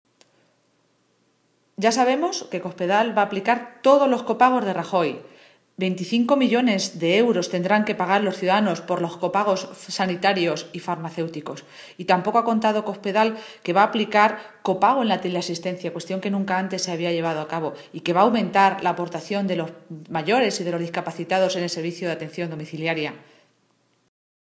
Momento de la rueda de prensa